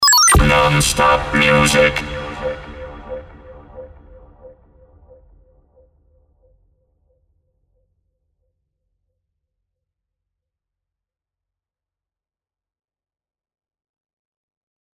• Eenstemmige Robo-Voice-jingle op 1 toonhoogte
• Standaard Sound Effects (SFX), zoals hoorbaar in voorbeeld.
Met Soundeffects
RoboVoice (eff) - Non Stop Music.mp3